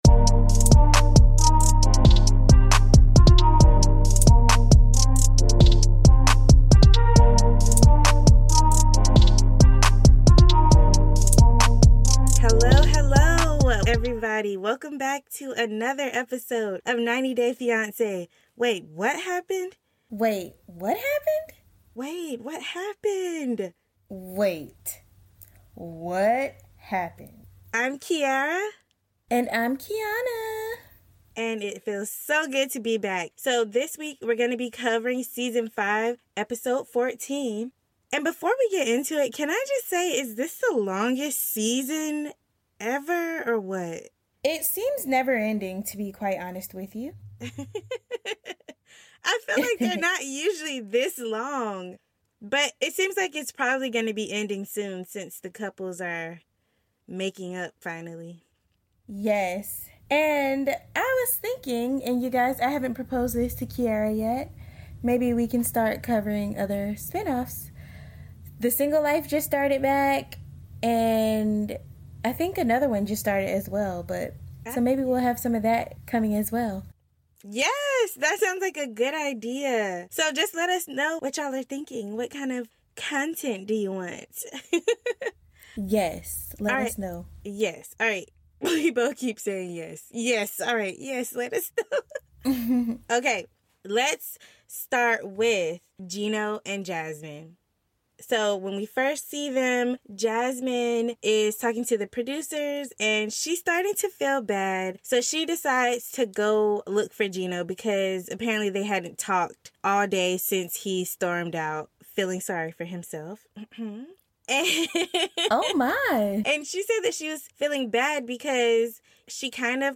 Join us as we discuss our favorite guilty pleasure... 90 Day Fiance! Hosted by your new favorite twins